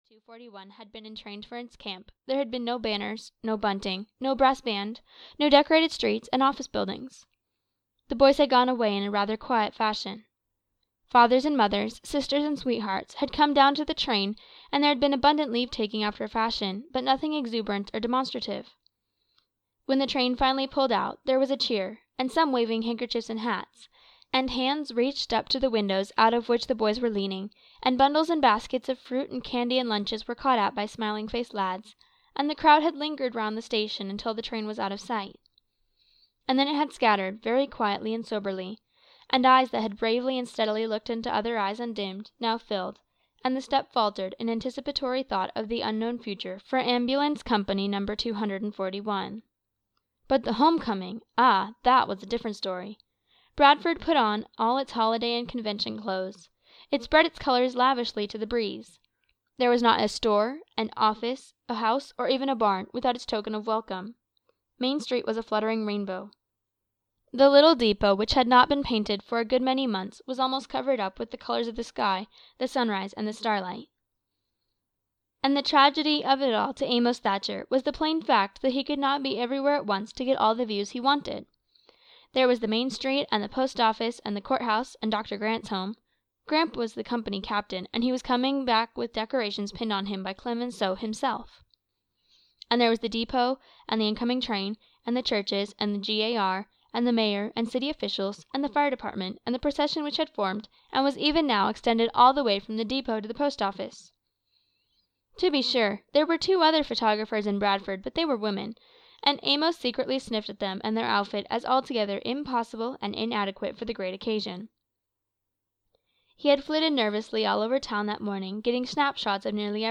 All The World (EN) audiokniha
Ukázka z knihy